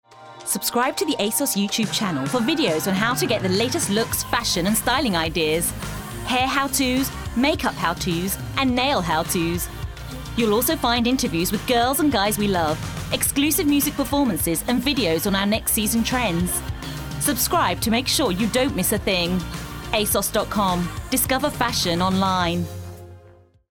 English (British)
Vibrant Assured Punchy